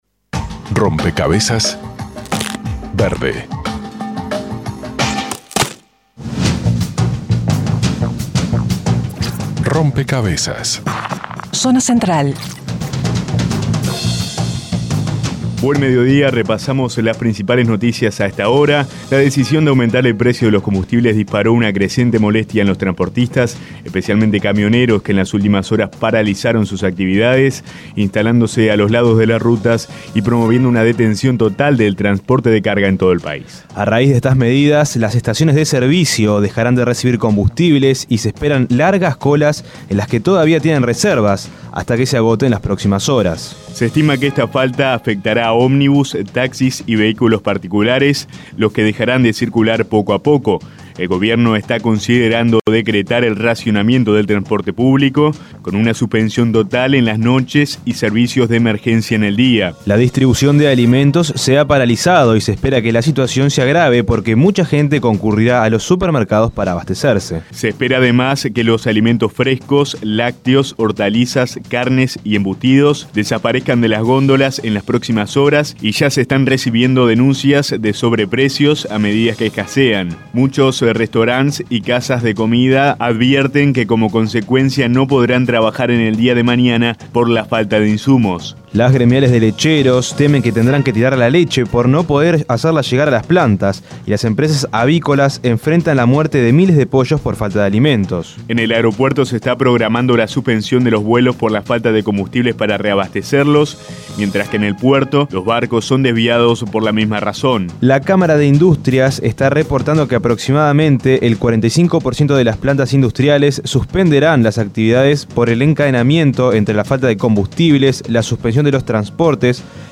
Simulando un falso informativo, planteó el desafío que significaría una crisis múltiple por el aumento en el precio de los combustibles.